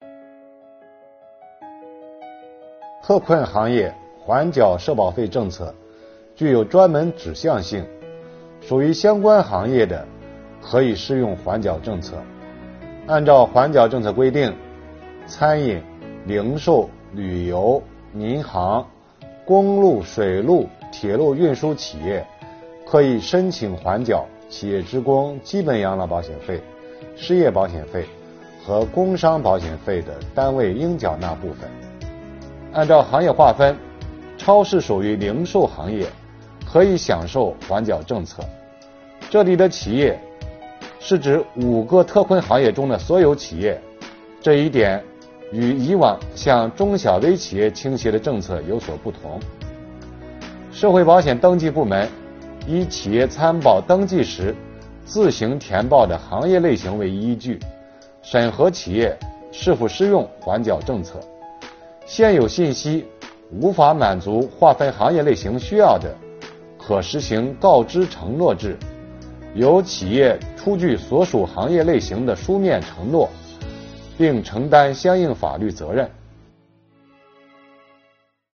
本期课程由国家税务总局社会保险费司副司长王发运担任主讲人，对公众关注的特困行业阶段性缓缴企业社保费政策问题进行讲解。